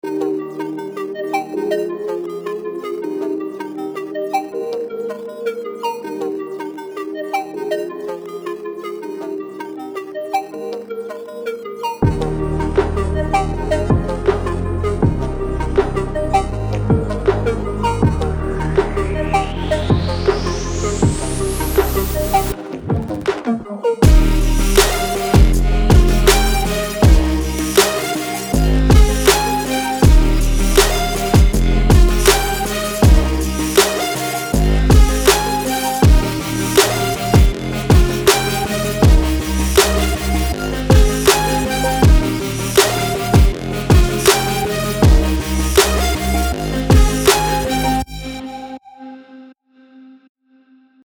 为现代音乐重新构想的怀旧合成器
房屋，陷阱，未来低音，流行乐，合成波，迪斯科流行乐